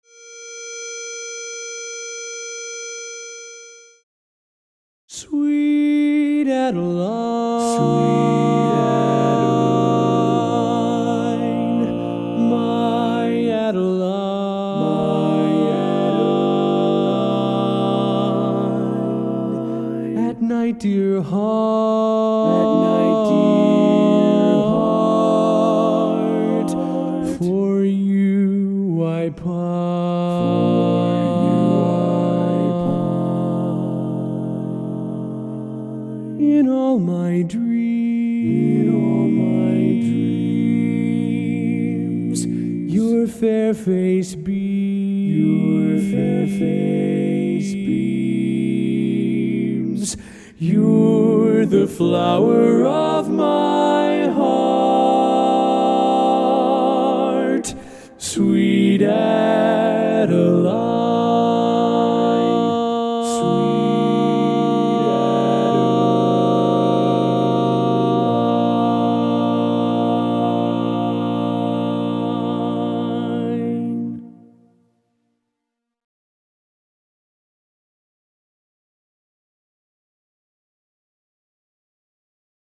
Barbershop
Lead